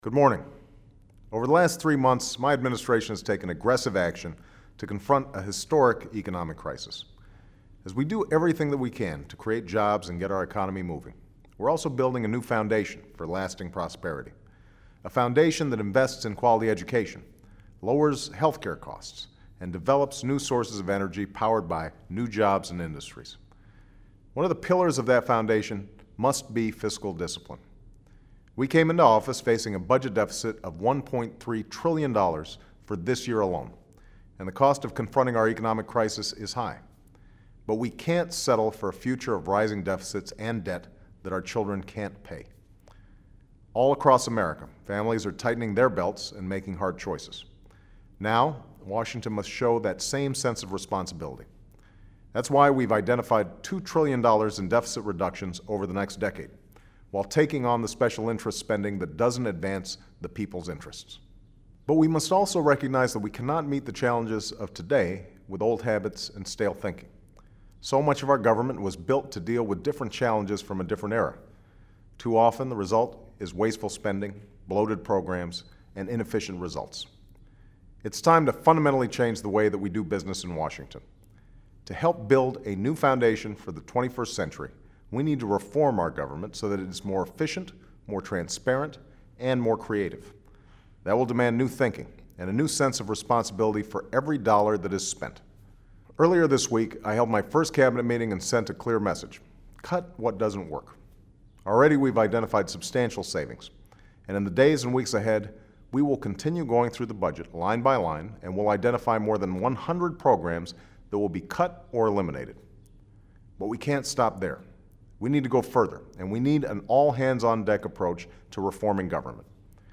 Your Weekly Address: April 25, 2009